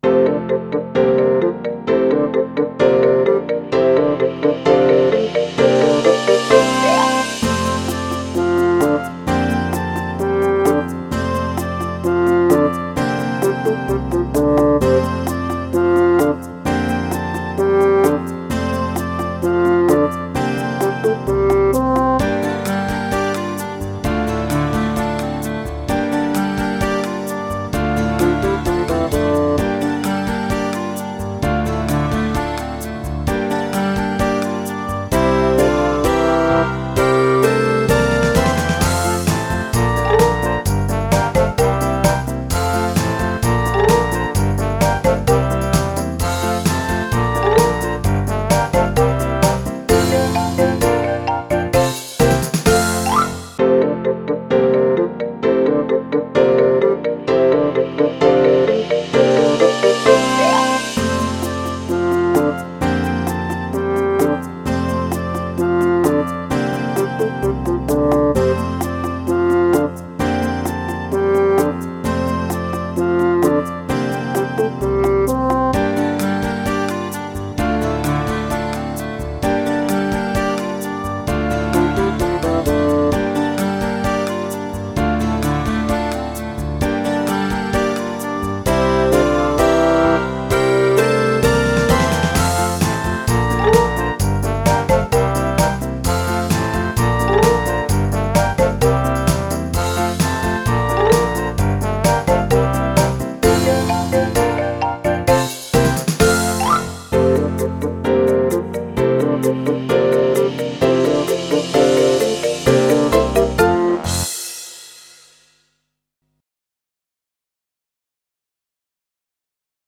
Song Backing Track MP3 - click